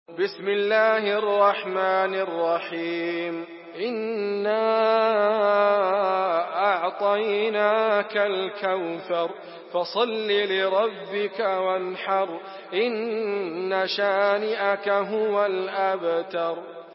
Surah الكوثر MP3 by إدريس أبكر in حفص عن عاصم narration.